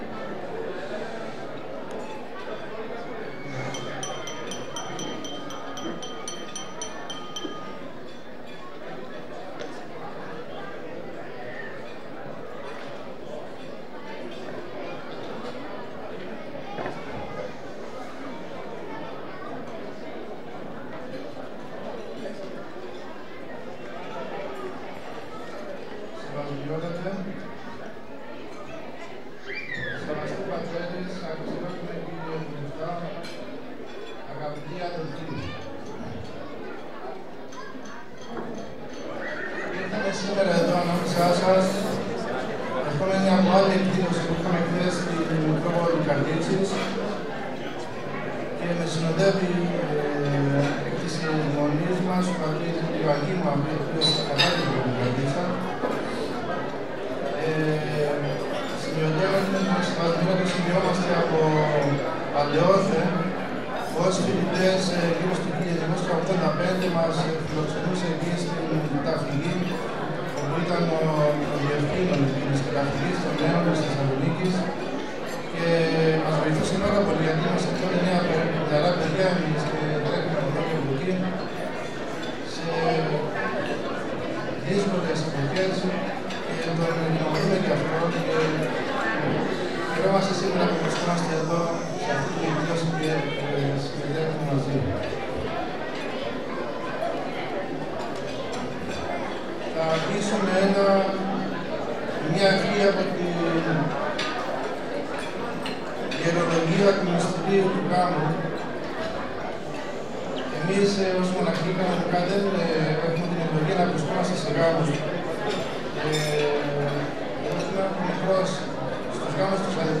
Παρουσία πολύτεκνων οικογενειών πραγματοποιήθηκε χθες, 9 Φεβρουαρίου οι βραβεύσεις στις πολύτεκνες οικογένειες από την Ιερά Μεγίστη Μονή Βατοπαιδίου και η ετήσια κοπή πίτας του Συλλόγου Πολυτέκνων Πιερίας στο Κέντρο Πιερίδα.